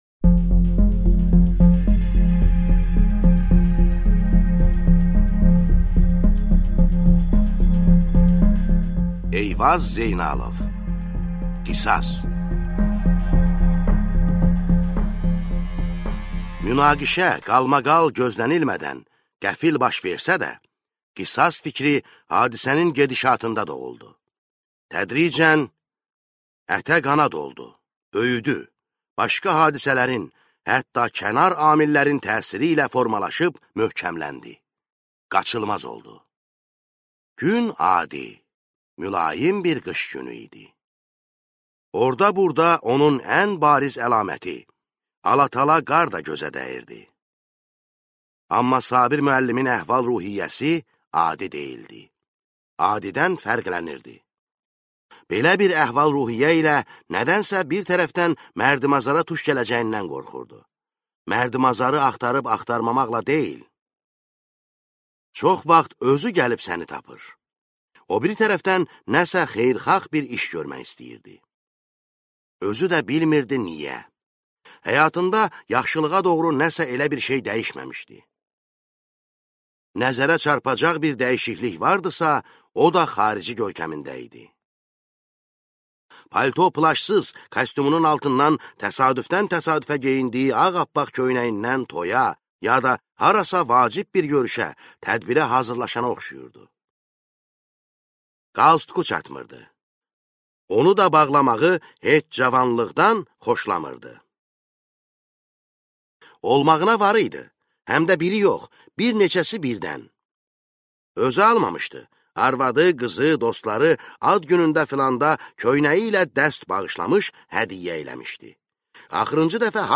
Аудиокнига Qisas | Библиотека аудиокниг